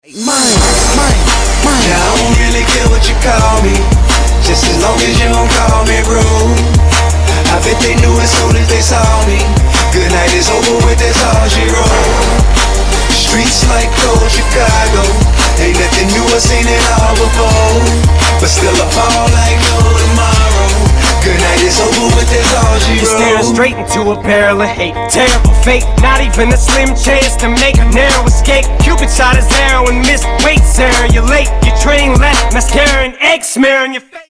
amerického speváka, rappera a producenta